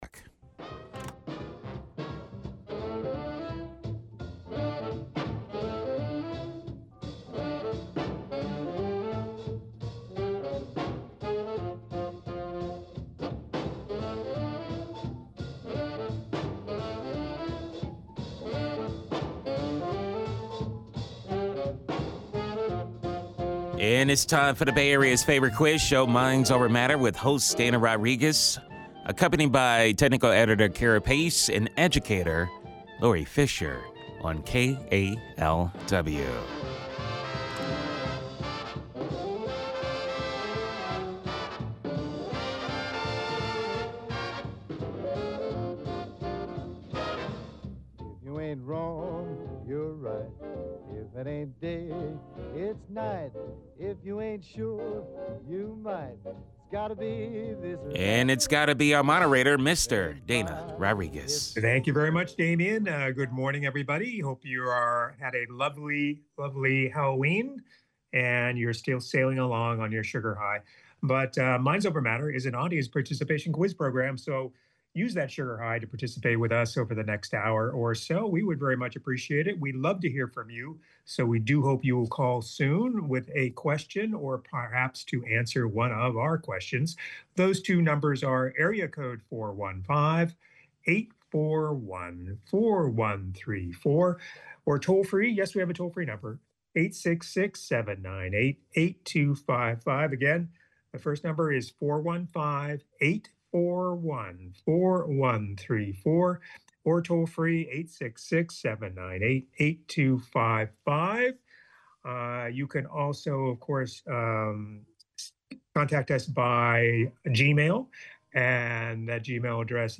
The Bay Area's favorite quiz show!